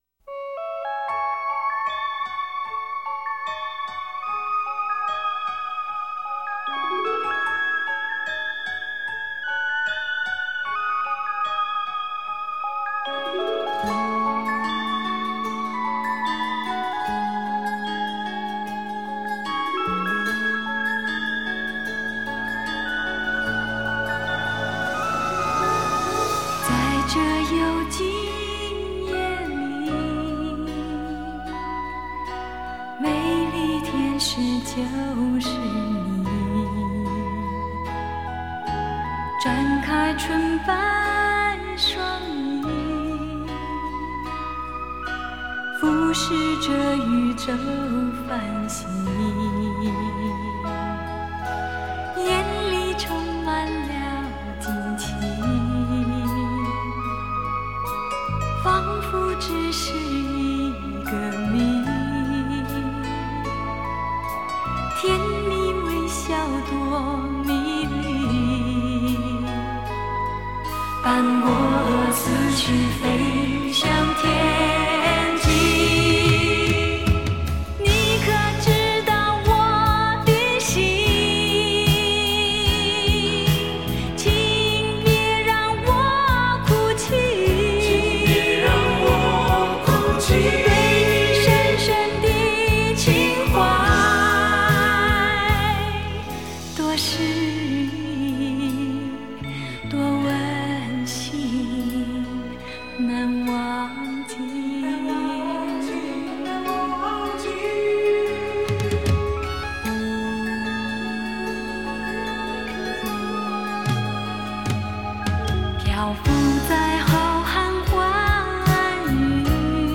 留声经典复刻版